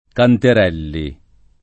[ kanter $ lli ]